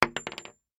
Bullet Shell Sounds
rifle_wood_3.ogg